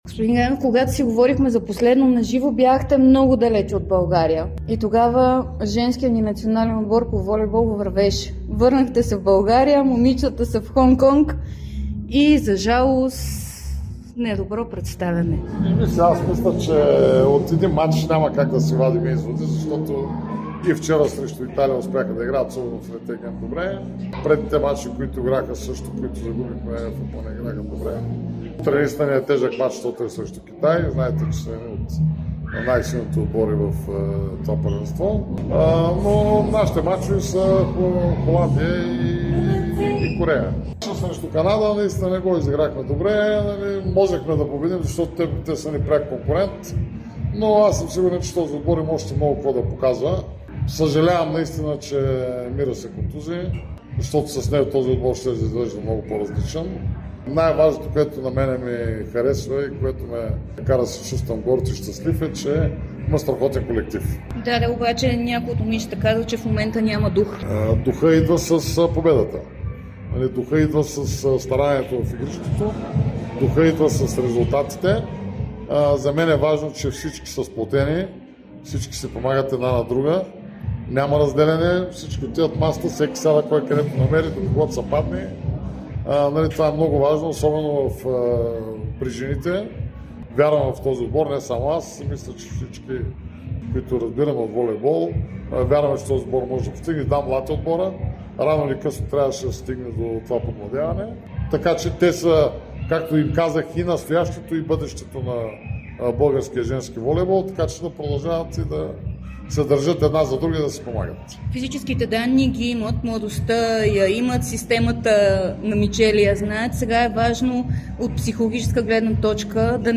Президентът на БФБ Любо Ганев даде специално интервю за Дарик радио и dsport.